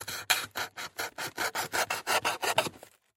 Звуки картона
Звук ножа, шуршащего по картону